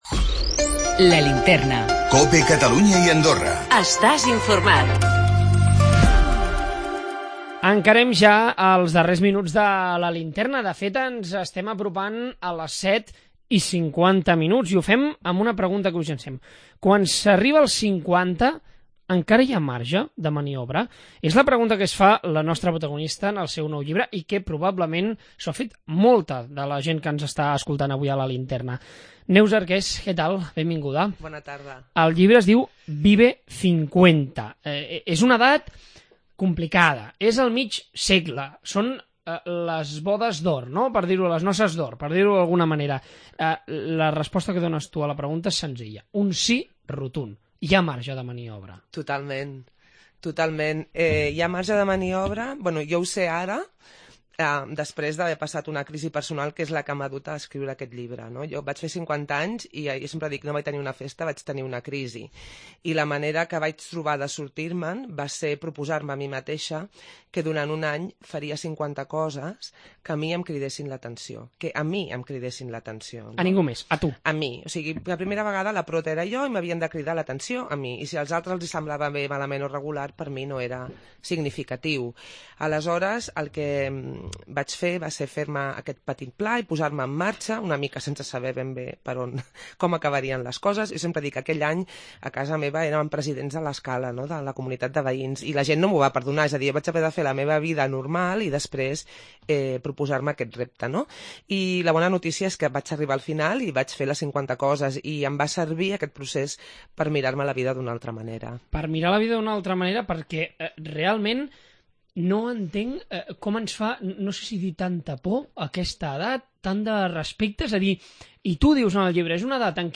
Hem parlat amb ella!